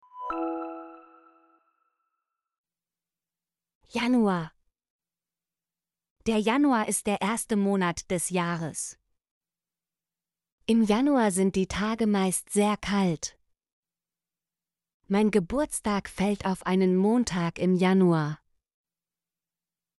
januar - Example Sentences & Pronunciation, German Frequency List